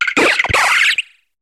Cri de Piclairon dans Pokémon HOME.